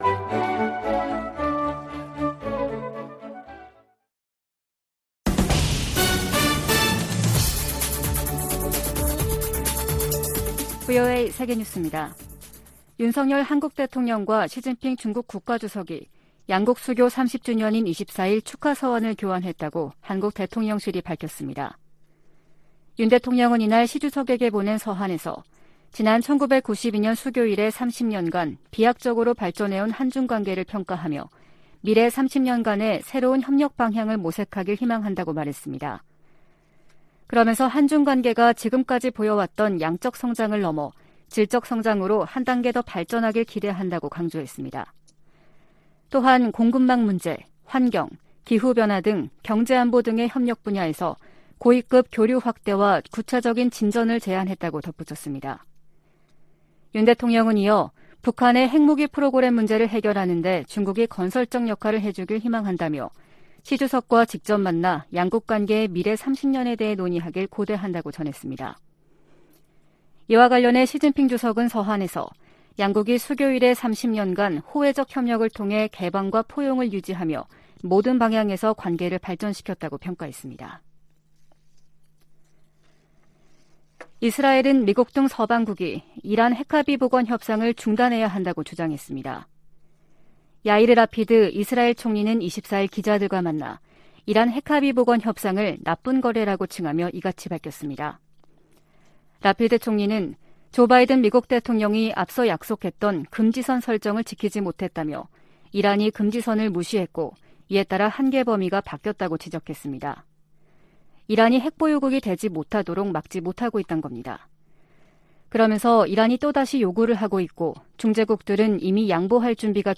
VOA 한국어 아침 뉴스 프로그램 '워싱턴 뉴스 광장' 2022년 8월 25일 방송입니다. 제10차 핵확산금지조약(NPT) 평가회의가 한반도의 완전한 비핵화를 지지하는 내용이 포함된 최종 선언문 초안을 마련했습니다. 에드워드 마키 미 상원의원은 아시아태평양 동맹과 파트너들이 북한의 핵 프로그램 등으로 실질적 위협에 직면하고 있다고 밝혔습니다. 미국 정부가 미국인의 북한 여행 금지조치를 또다시 연장했습니다.